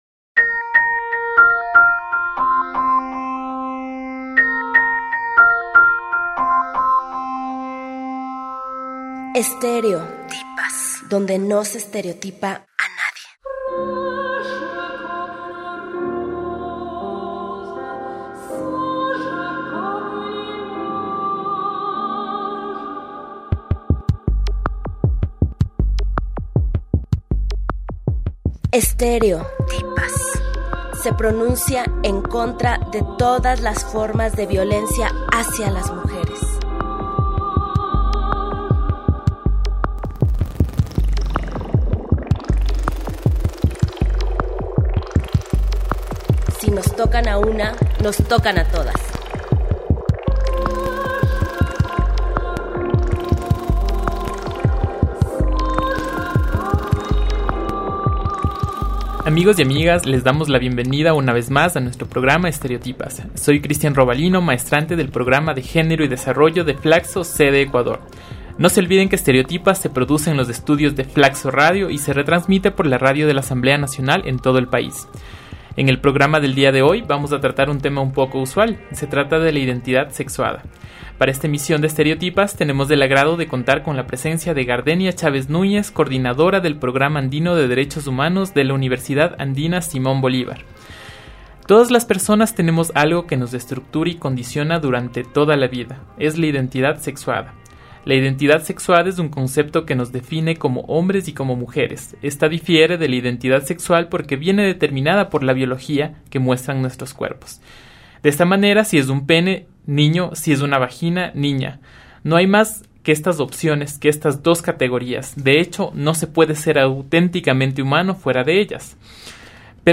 La invitada expuso varios ejemplos de cómo cada cultura asigna cierto tipo de reglamentación en torno al ejercicio de la sexualidad como forma en que se rigen y se organizan las diferentes sociedades en el mundo.